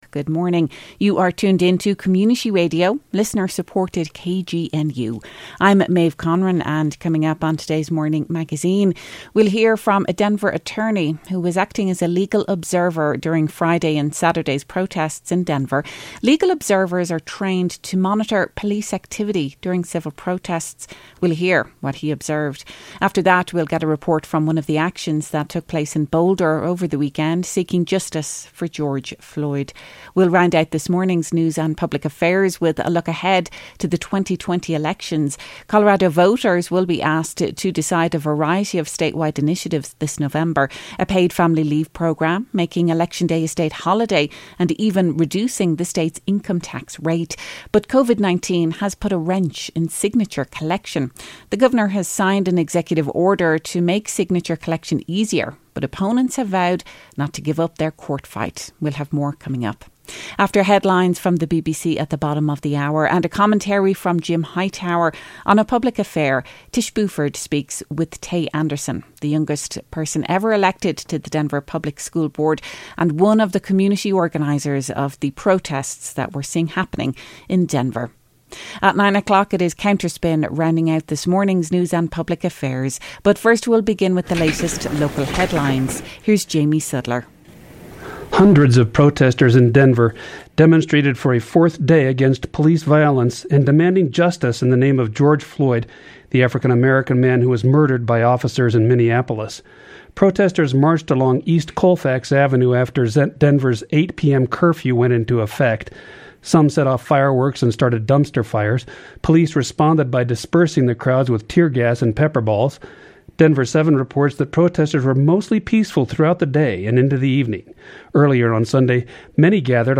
A local attorney tells us what he saw when working as a legal observer this weekend monitoring police activity at the ongoing protests in Denver. Then, we get a report from a recent action in Boulder seeking justice for George Floyd followed by news related to this November’s election and the fight over how signatures are collected.